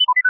techBleep.ogg